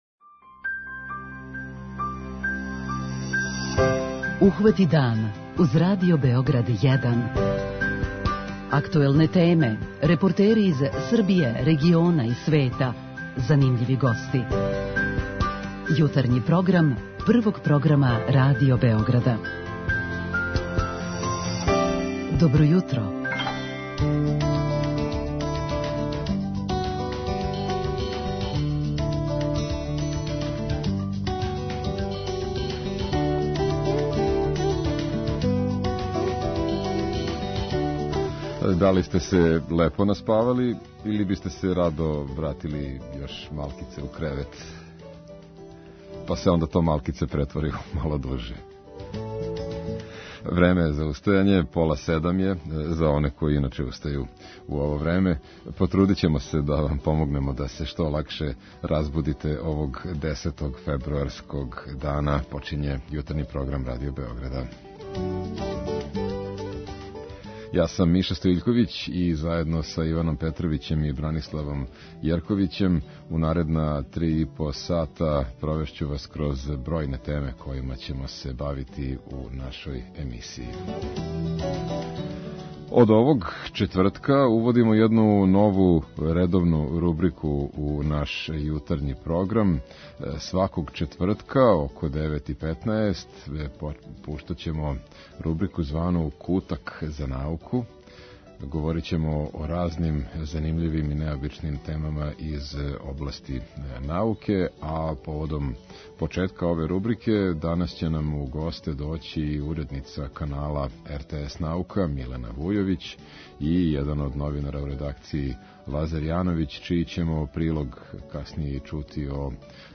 Пробудите се уз Радио Београд 1!